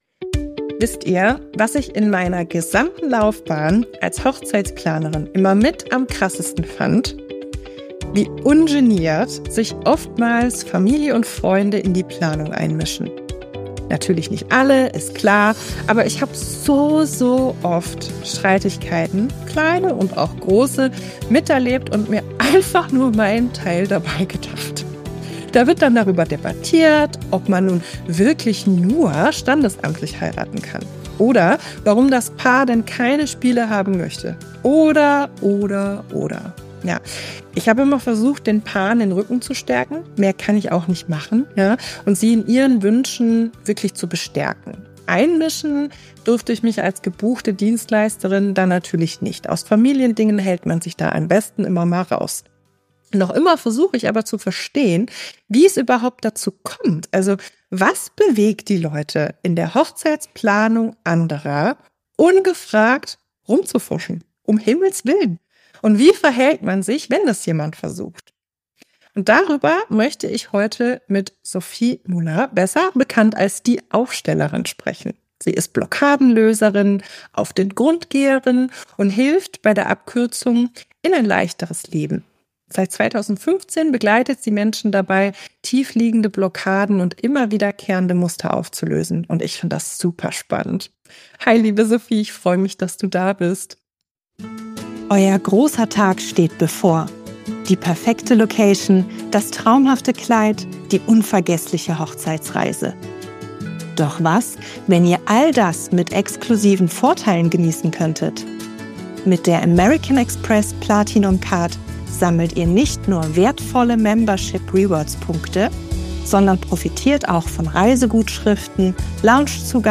Die beiden reden darüber, warum sich Angehörige so stark in die Planung einmischen, welche psychologischen und familiären Dynamiken dahinterstecken und wie Ihr als Paar mit solchen Situationen umgehen könnt.